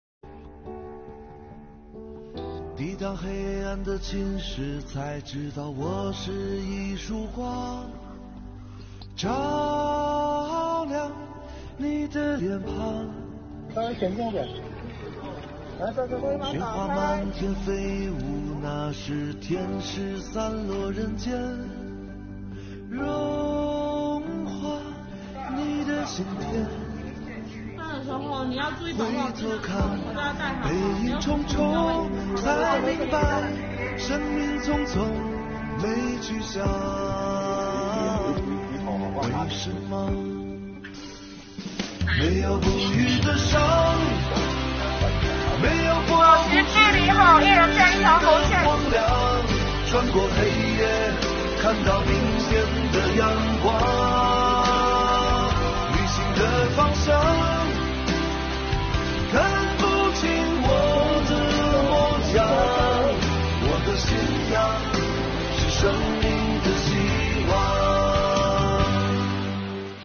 背景音乐